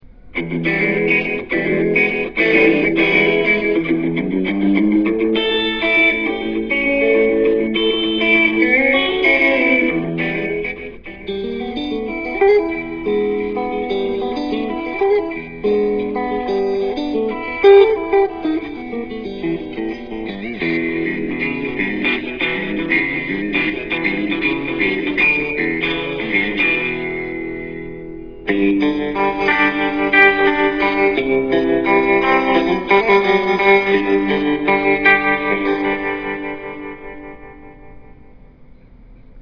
These were recorded off the Line 6 Sampler CD. If you want to hear the full capabilites of the amp I definetly suggest that you inquire Line 6 about one of these CD's. These samples were recorded directly on to my computer so the definition and clarity isn't perfect.
This is the Fender Deluxe. Notice how clean the sound can get. It is the classic fender tone that is perfect for blues, country or rock styles.
fenderdluxe.ra